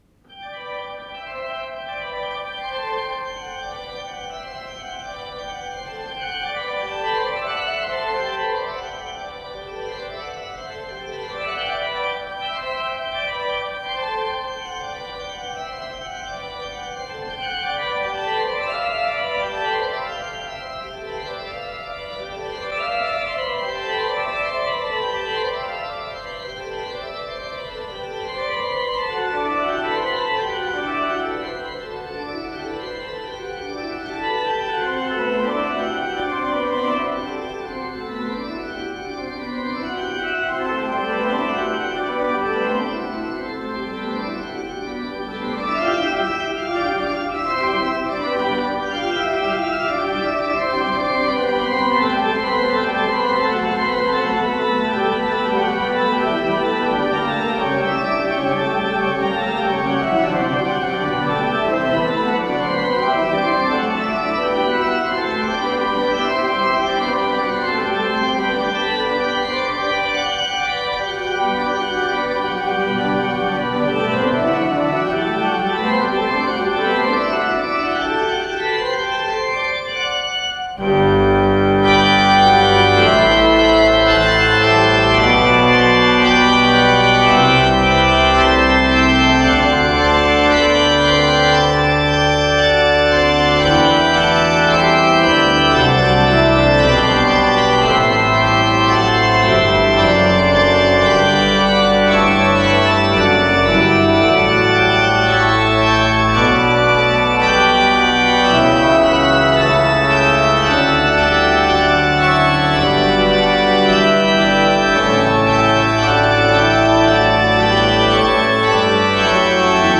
с профессиональной магнитной ленты
орган
ВариантДубль моно